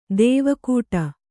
♪ dēva kūta